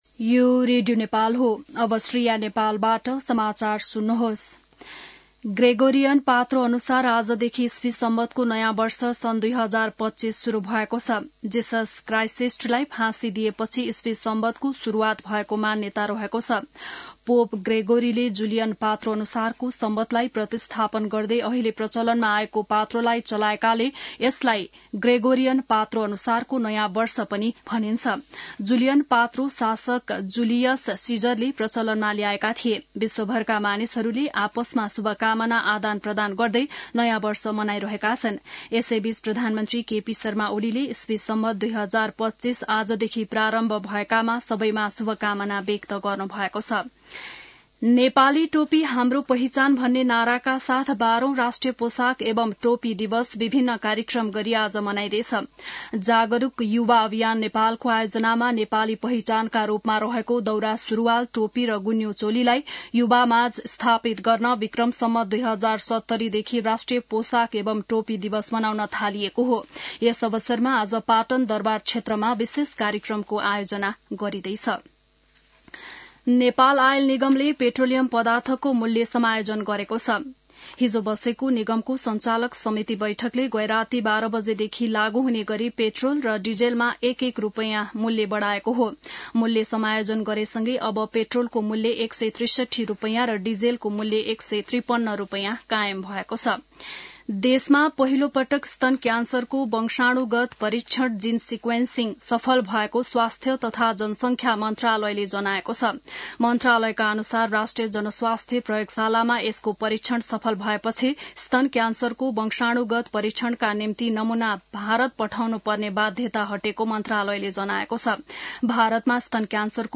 बिहान १० बजेको नेपाली समाचार : १८ पुष , २०८१